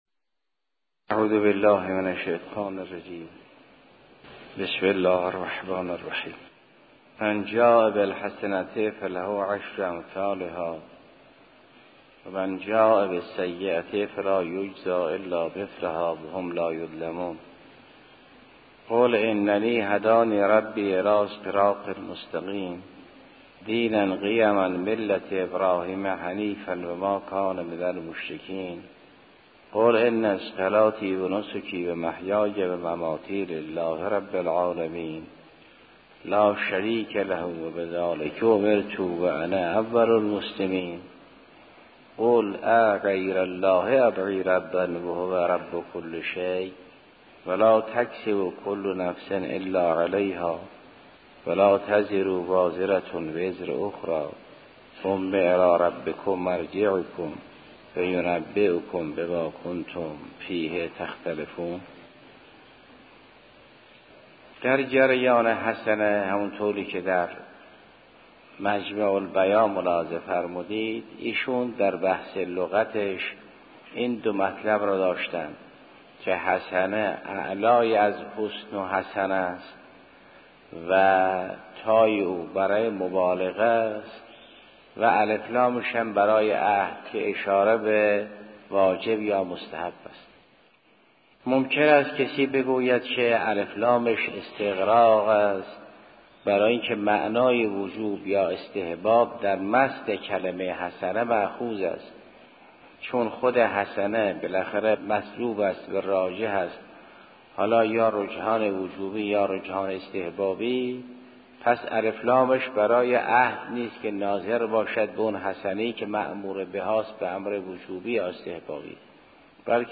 تفسیر سوره انعام جلسه 160